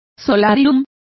Complete with pronunciation of the translation of solaria.